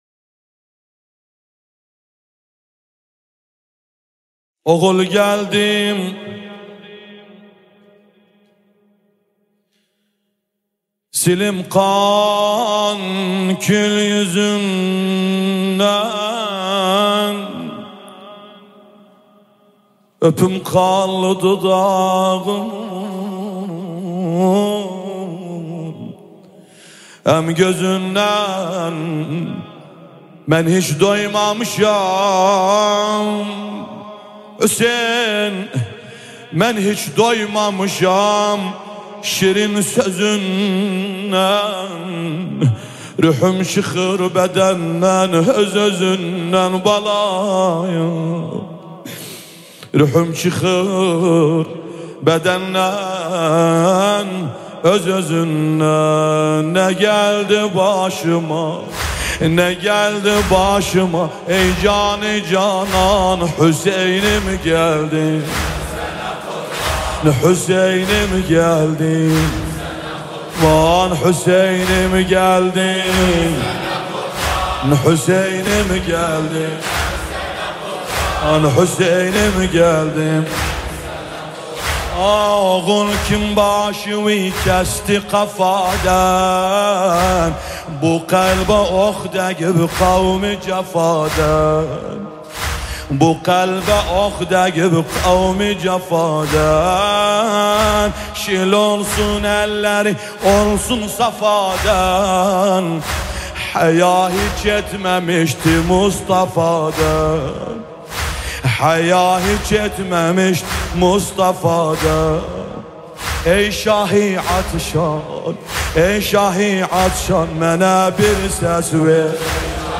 نوحه ترکی عراقی
با صدای دلنشین